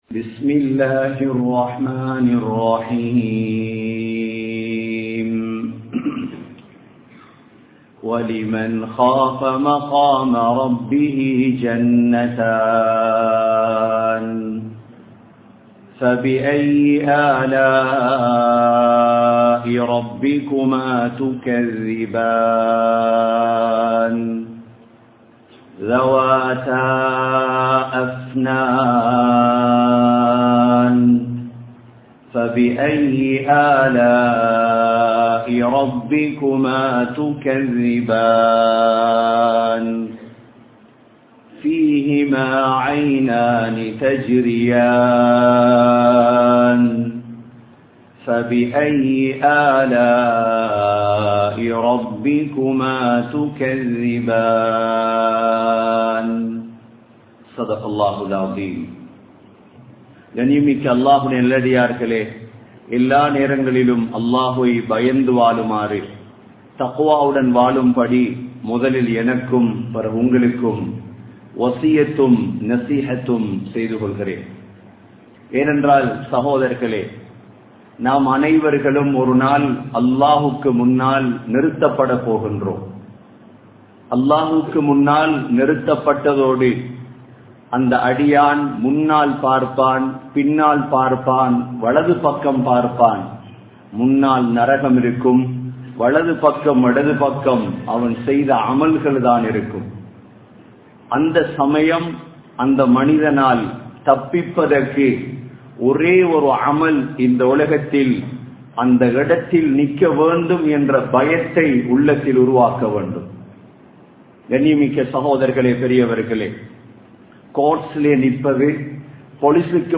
Thaqwa & Suvarkam (தக்வா & சுவர்க்கம்) | Audio Bayans | All Ceylon Muslim Youth Community | Addalaichenai
Colombo 11, Samman Kottu Jumua Masjith (Red Masjith)